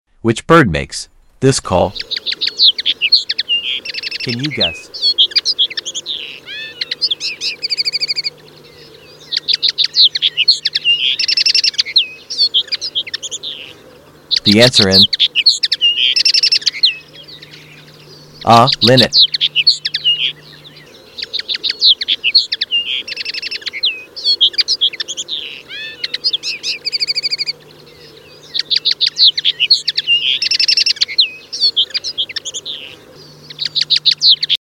Which bird makes this call.?..Can sound effects free download